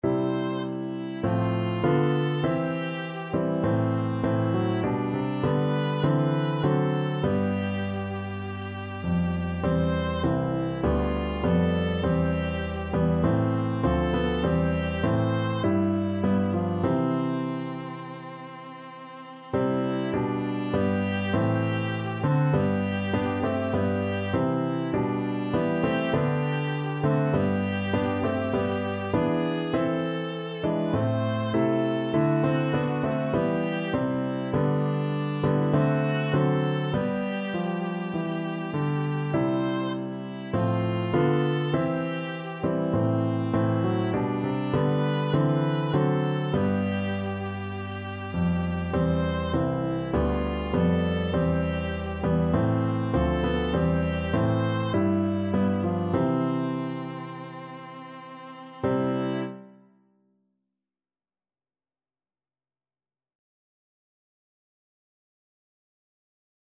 Viola
4/4 (View more 4/4 Music)
C major (Sounding Pitch) (View more C major Music for Viola )
Christian (View more Christian Viola Music)